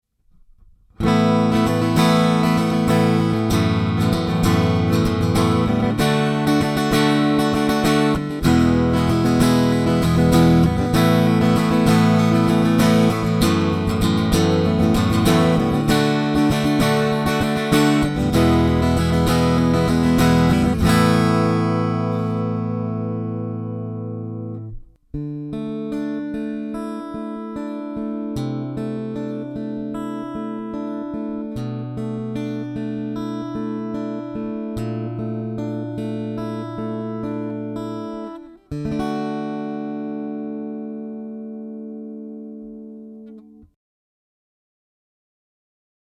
アコギ弦をはった状態の音
いずれもピエゾピックアップで拾い、AG-STOMPを通してのライン録音です。
アコギ弦をはったほうがやはりアコギに近い音がしますね〜
acoustic.mp3